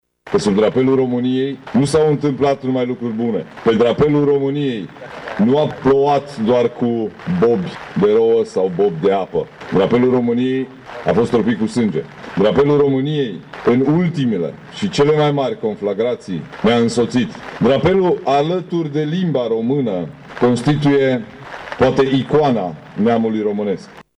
Președintele Consiliului Județean Mureș, Ciprian Dobre, a subliniat că cei care au apărat drapelul nu au ținut cont de condițiile meteo sau de cele de pe front.